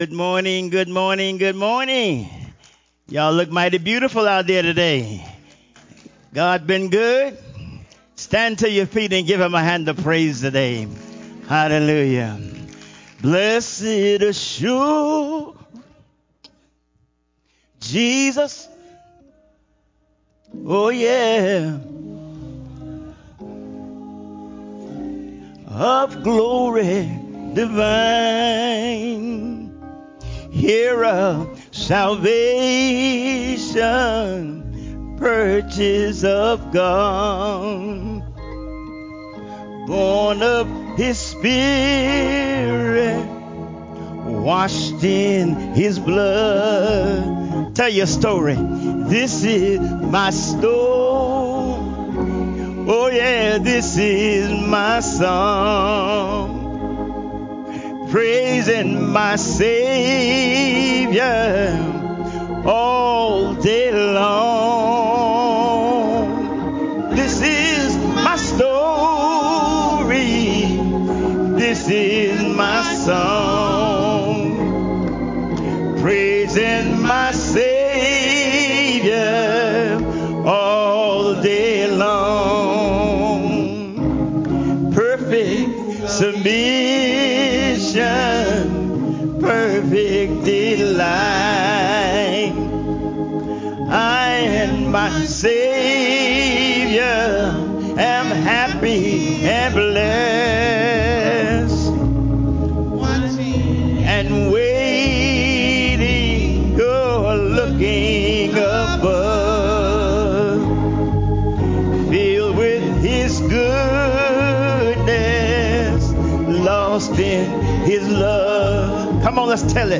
7:30 A.M. Service: What Are You Worth?